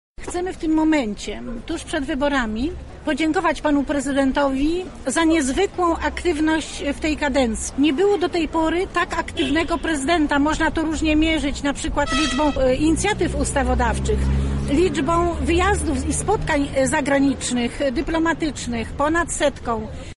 Wiec poparcia lubelskich kobiet dla Andrzeja Dudy.
Nie możemy przerwać tego pomyślnego ciągu zdarzeń– mówi posłanka Gabriela Masłowska